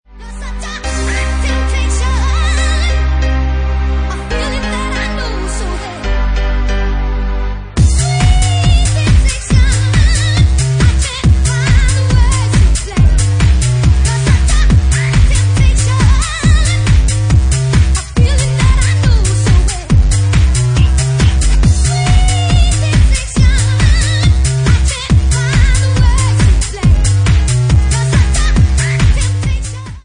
Genre:Bassline House
138 bpm